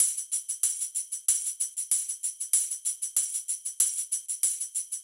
SSF_TambProc1_95-03.wav